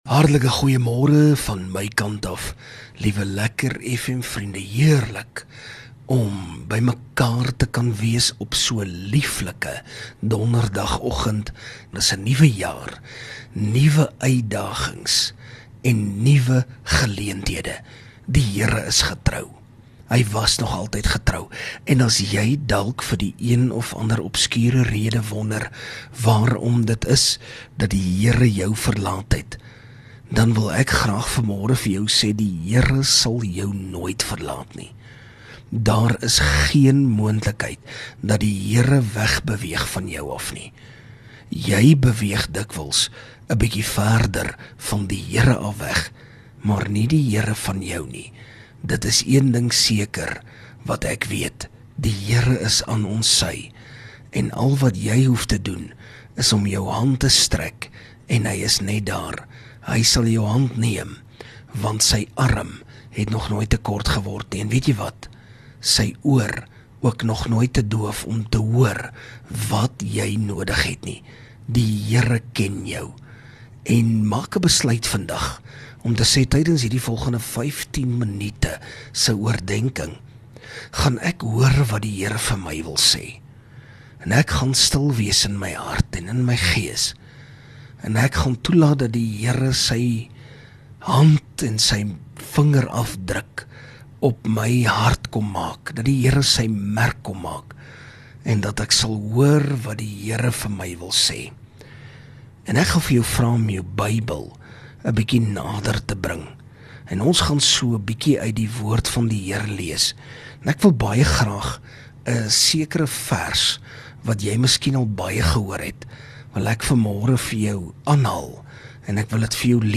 LEKKER FM | Oggendoordenkings